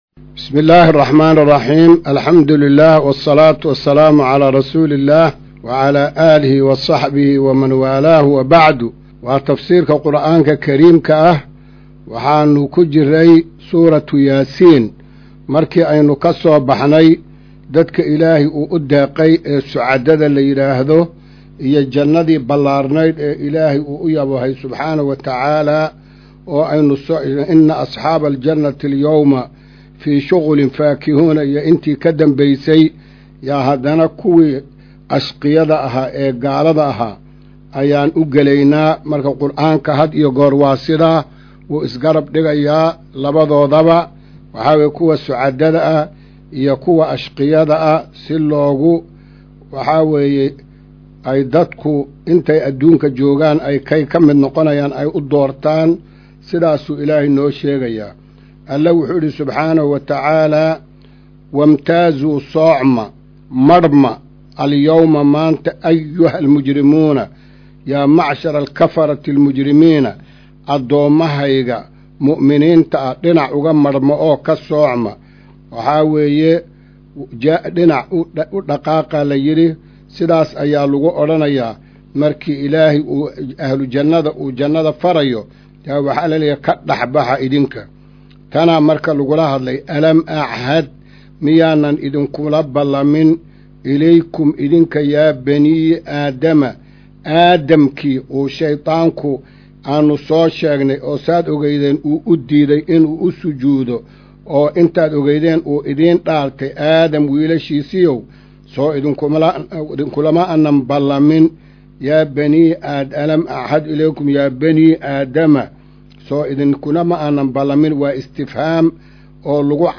Casharka-210aad-ee-Tafsiirka.mp3